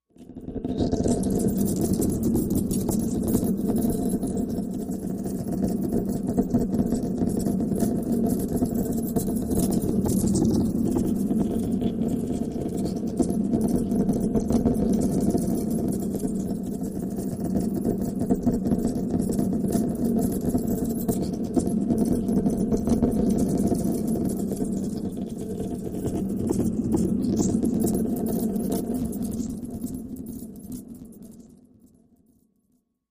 Stress Melt, Machine, Sparks, Molten Steel, Motion, Arcs